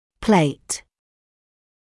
[pleɪt][плэйт]пластина; пластинка